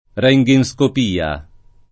röntgenscopia [rHntgenSkop&a o